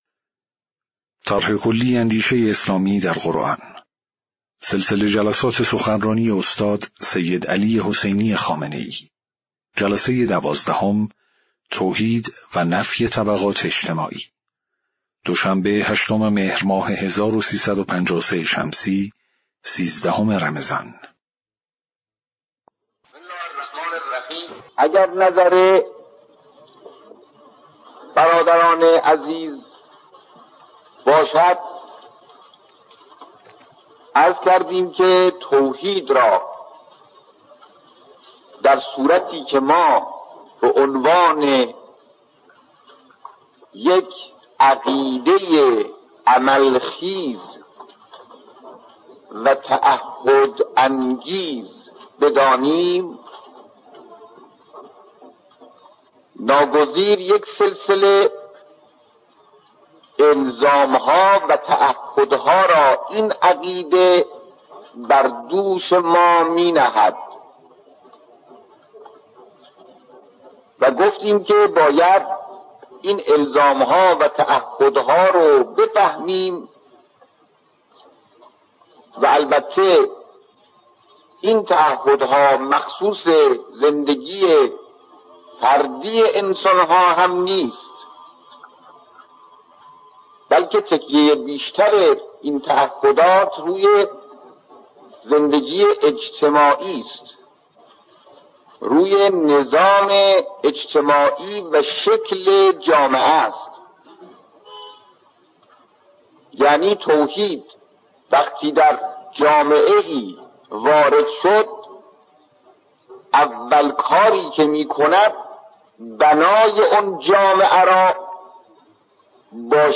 صوت/ جلسه‌ دوازدهم سخنرانی استاد سیدعلی‌ خامنه‌ای رمضان۱۳۵۳